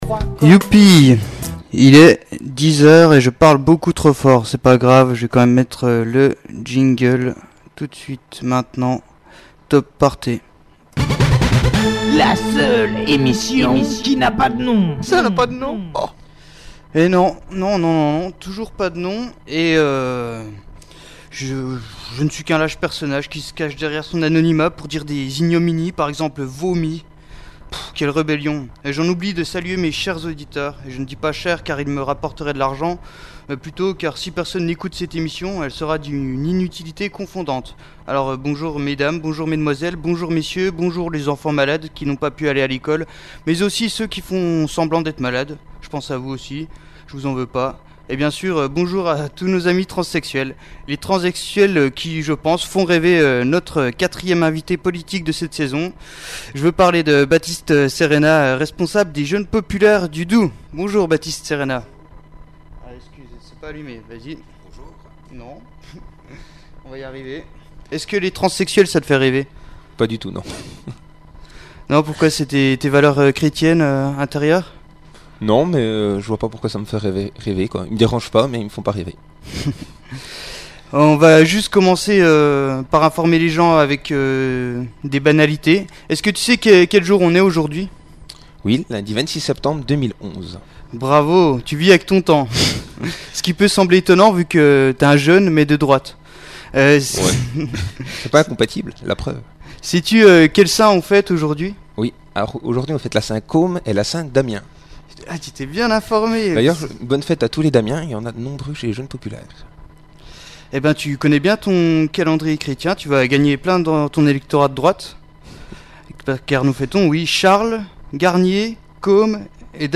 J’ai juste coupé une partie des problèmes de micro du début